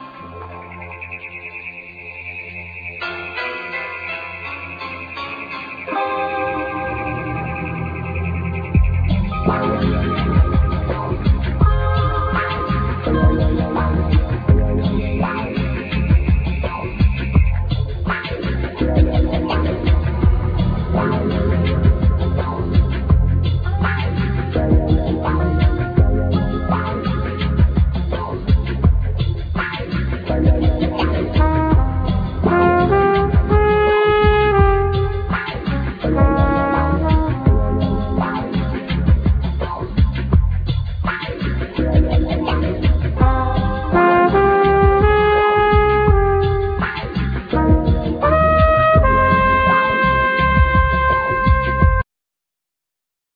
Trumpet,Flugelhorn
Bass,Keyboards,Guitar,Samples
Fender Rhodes
Drums
Vocals